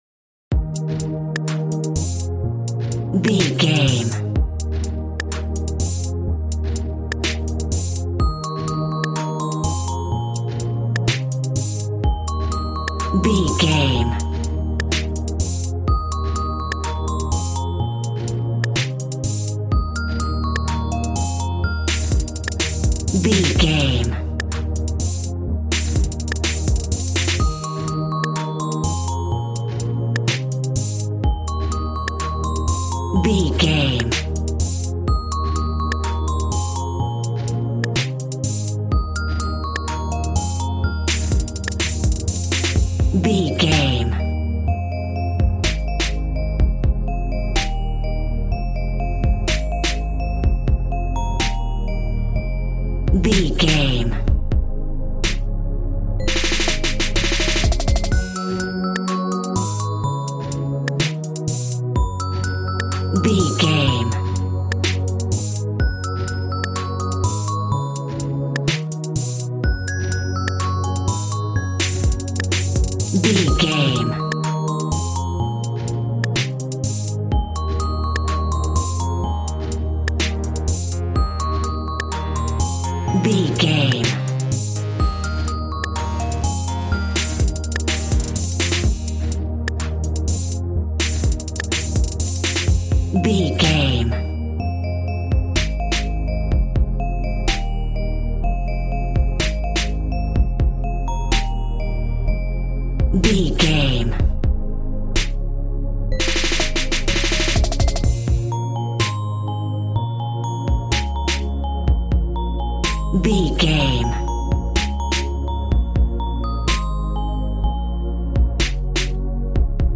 Aeolian/Minor
B♭
groovy
synthesiser
drums
piano